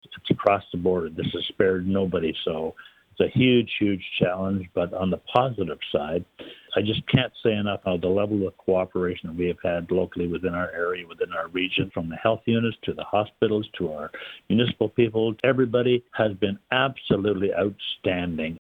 Daryl Kramp spoke to Quinte News recently to reflect on the past year and the challenges posed in 2020.